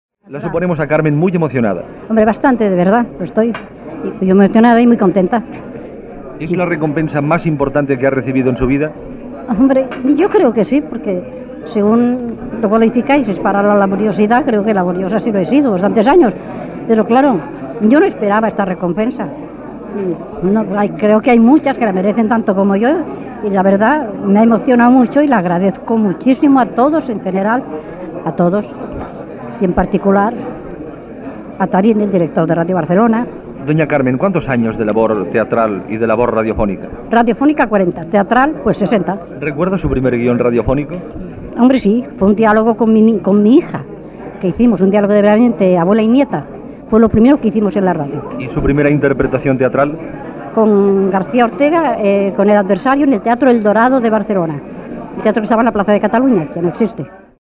Rep el premi 'Abeja de oro' - Radio Barcelona, 1969
Àudios: Ràdio Barcelona